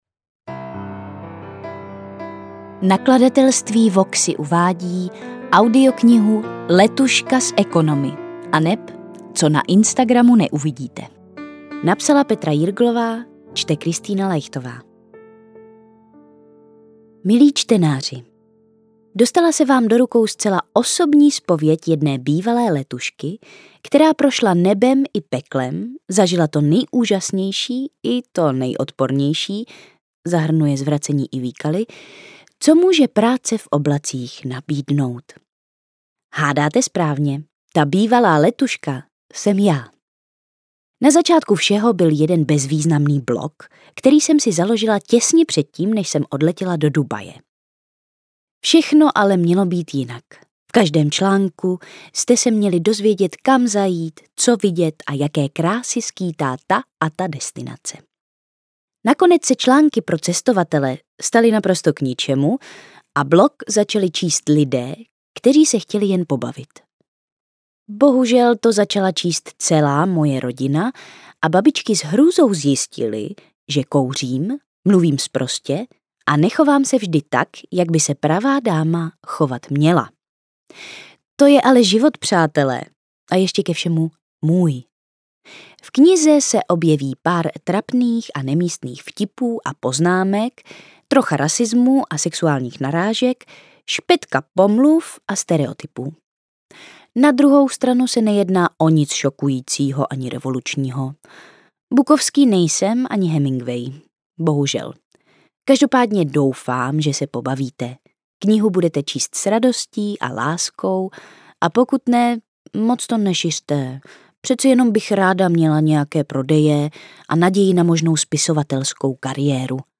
Audiokniha je shrnutím roku jedné letušky v Dubaji. Objeví se v ní pár trapných a nemístných vtipů a poznámek, trocha rasismu a sexuálních narážek, špetka pomluv a stereotypů.